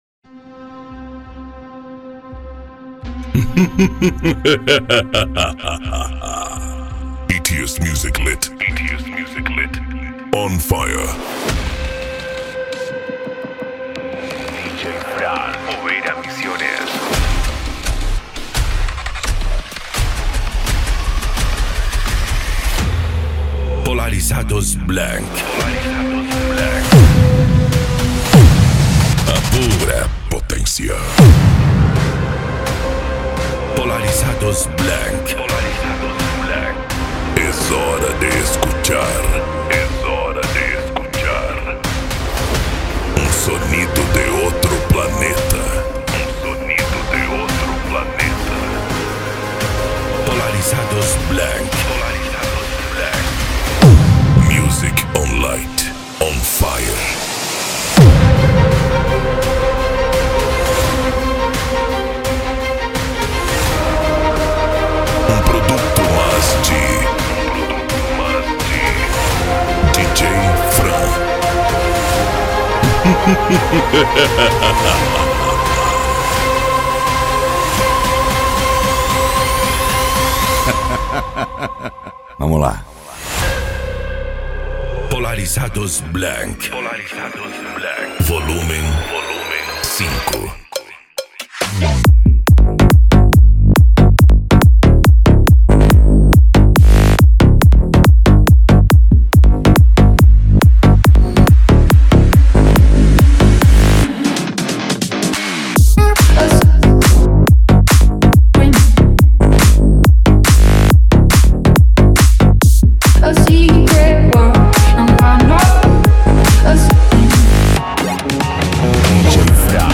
Psy Trance